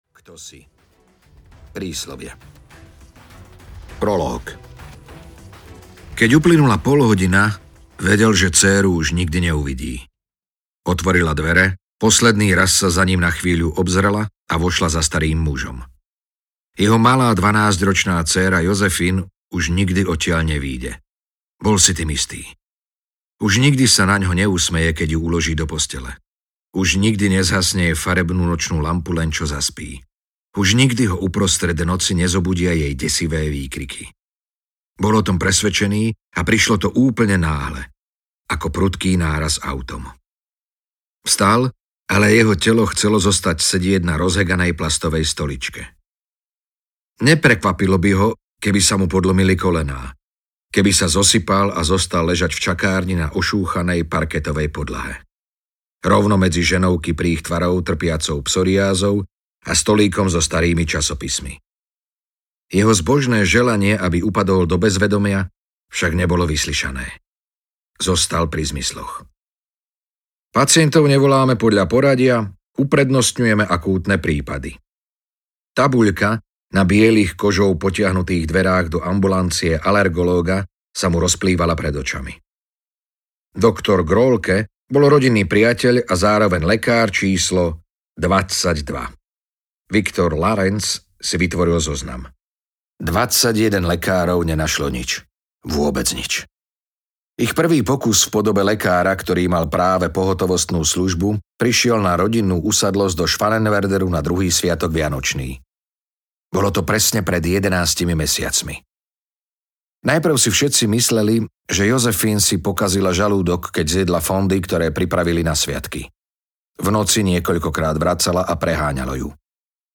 Terapia audiokniha
Ukázka z knihy